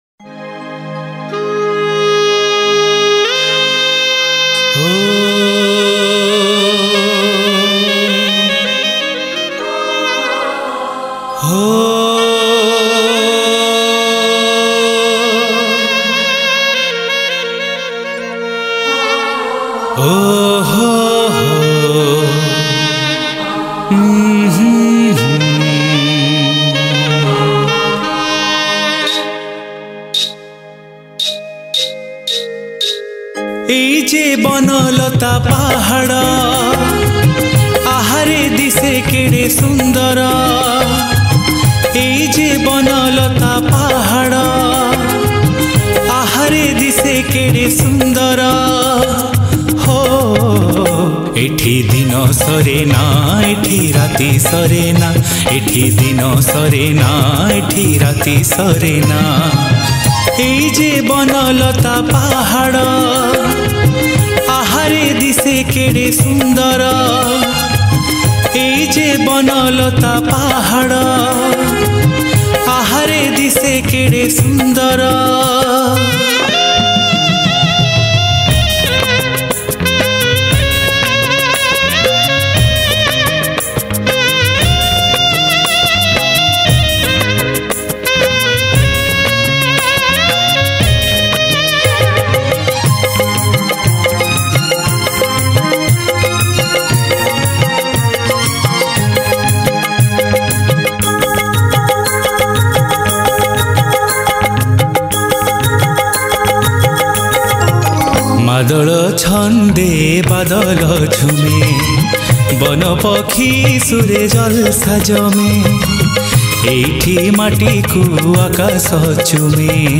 Old Odia Romantic Song